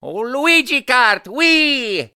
One of Luigi's voice clips in Mario Kart Wii